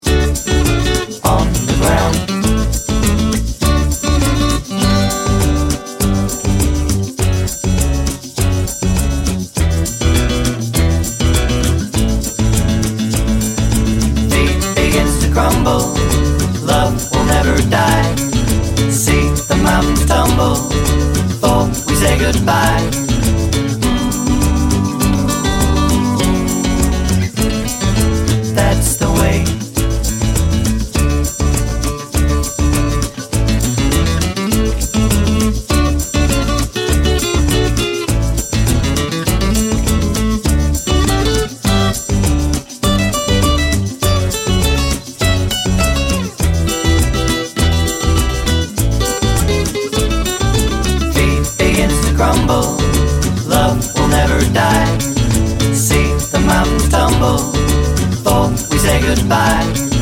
Much Shorter Version Pop (1970s) 2:24 Buy £1.50